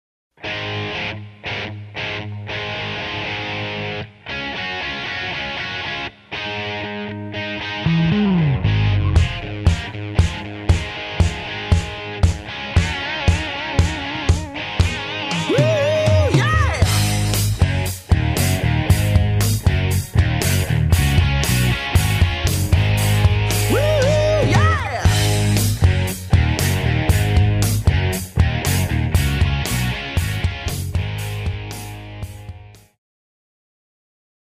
Backing Track without Vocals for your optimal performance.